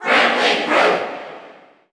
Category: Crowd cheers (SSBU) You cannot overwrite this file.
King_K._Rool_Cheer_English_SSBU.ogg